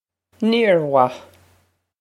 Pronunciation for how to say
neer wah
This is an approximate phonetic pronunciation of the phrase.